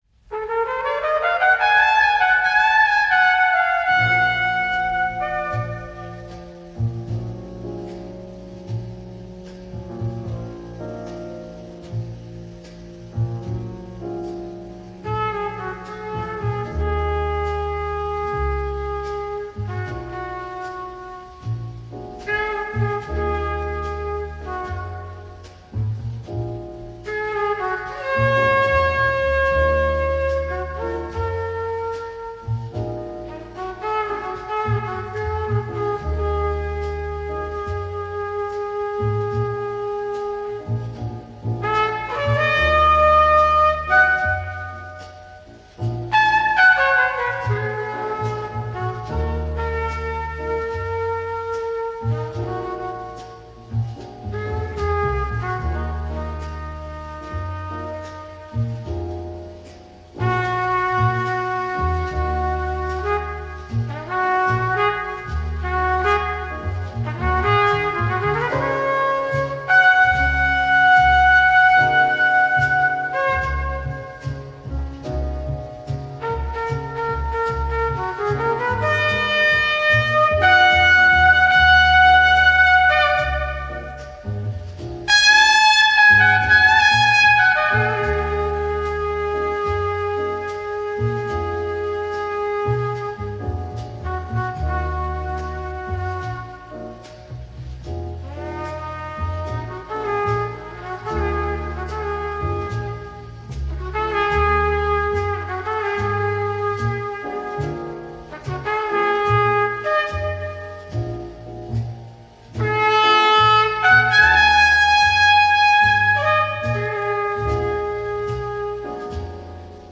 موسیقی جَز موسیقی Jazz